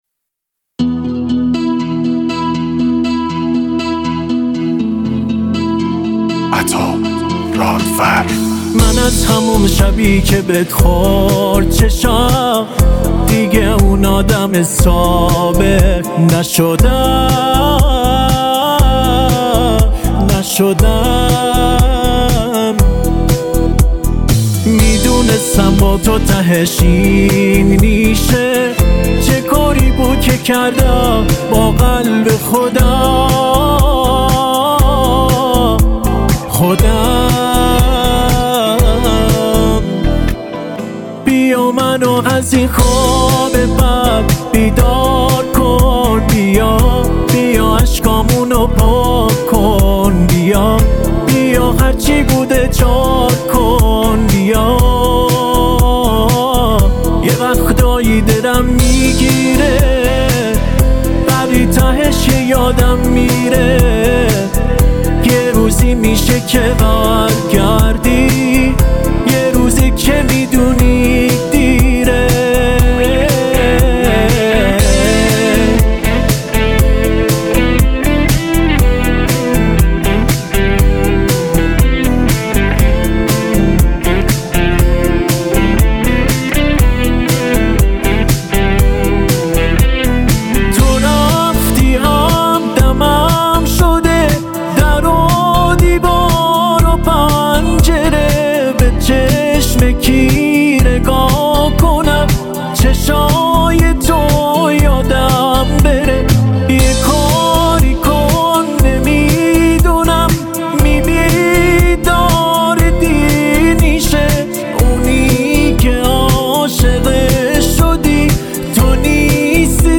پاپ
آهنگ با صدای زن
اهنگ ایرانی